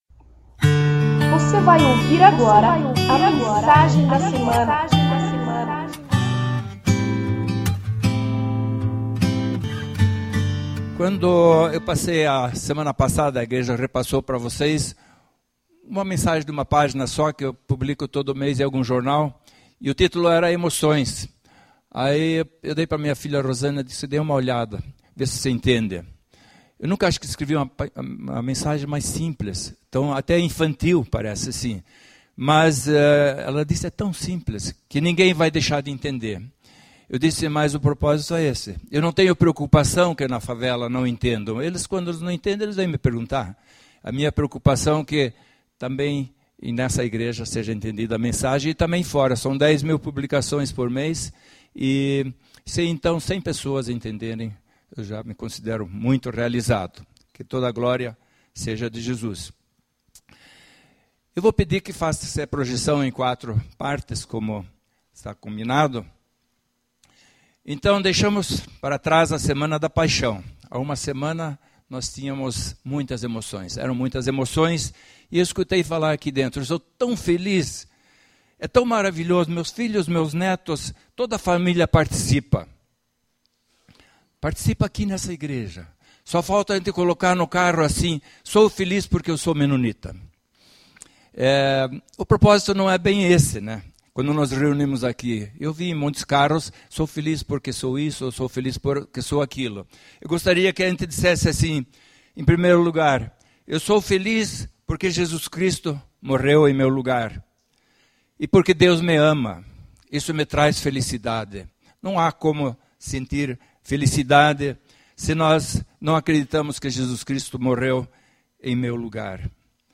Igreja Evangélica Menonita - Água Verde